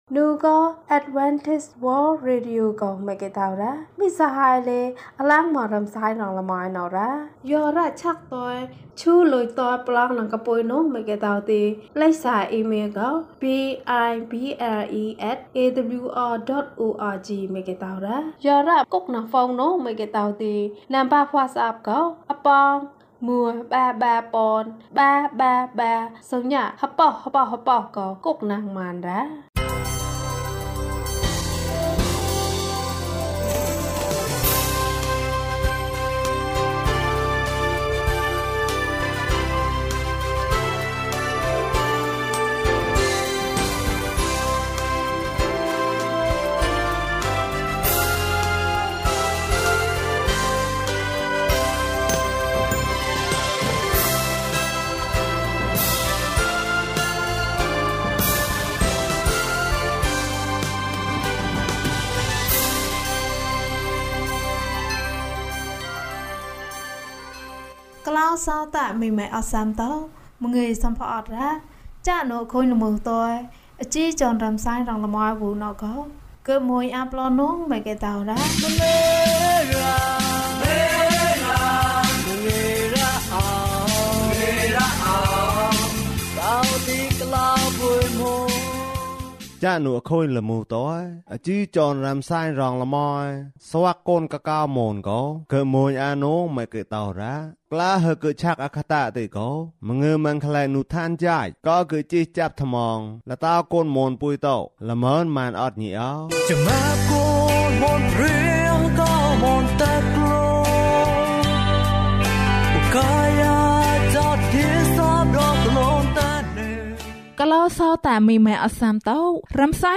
တောက်ပသောဘဝ။ ကျန်းမာခြင်းအကြောင်းအရာ။ ဓမ္မသီချင်း။ တရား‌ဒေသနာ။